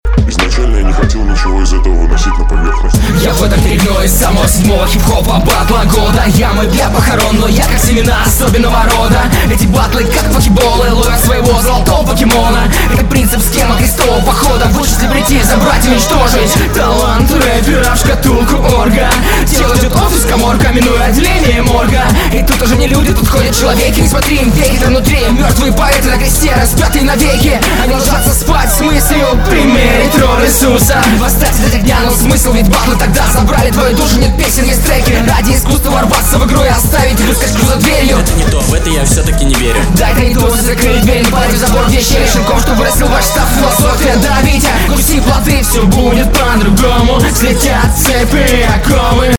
Прослушиванию мешает каша из акапелл и бэков. Кое-где слишком долго одним потоком выпаливаешь текст. Но в целом прослеживается живость и энергичность исполнения, хоть по биту и идешь неровно